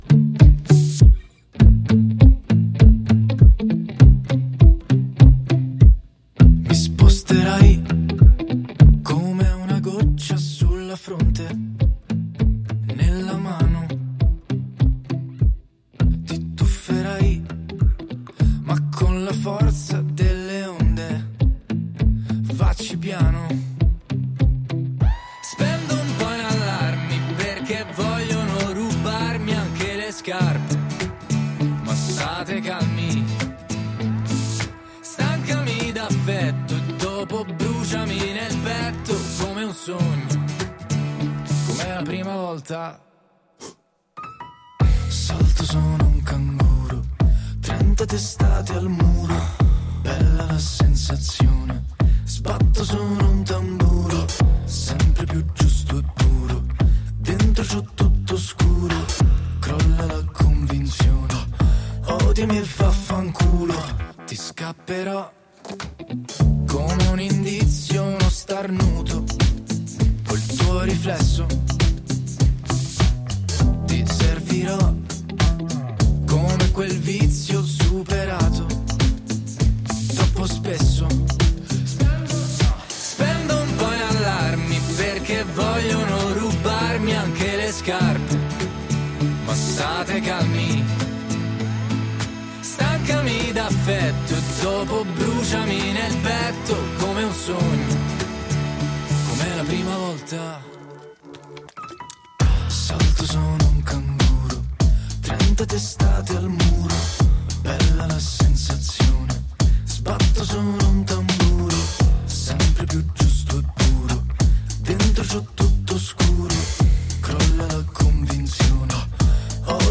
Intervista e live set a Rca Fulminacci